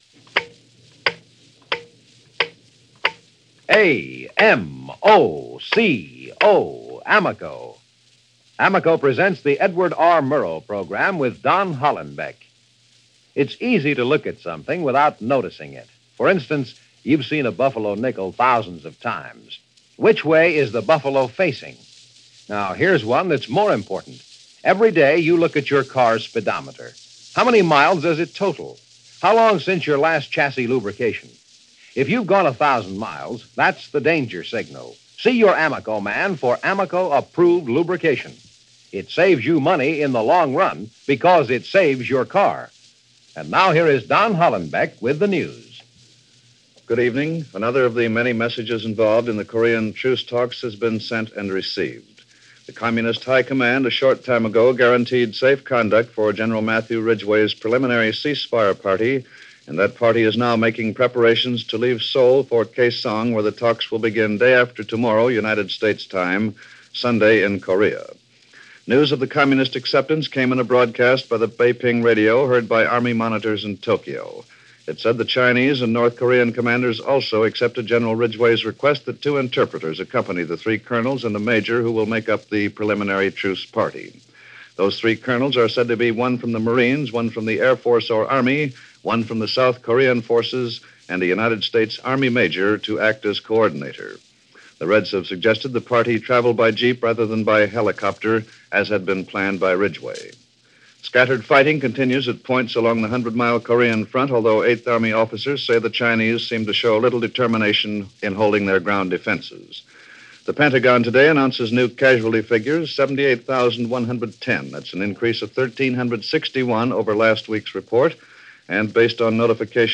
July 5, 1951 - Korean War - Amid Rubble, Olive Branches And Hopeful Signs -News for this day in 1951 with Don Hollenbeck - Past Daily.